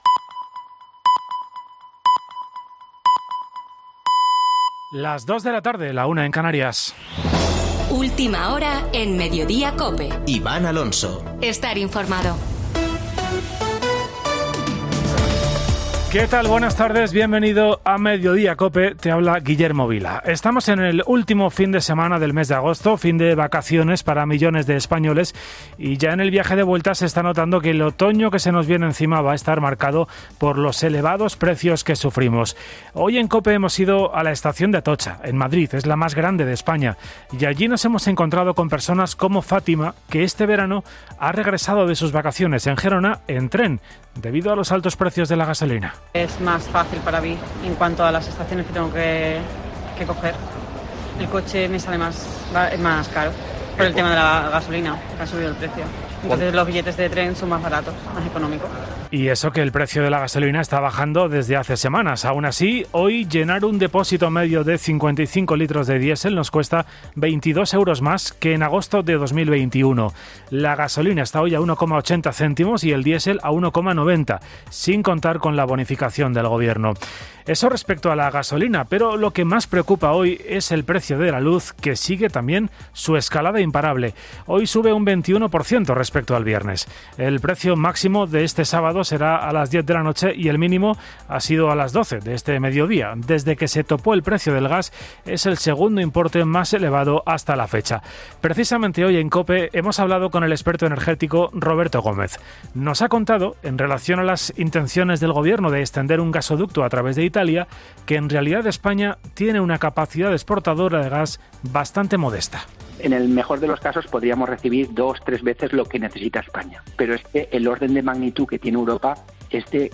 Boletín de noticias de COPE del 27 de agosto de 2022 a las 14.00 horas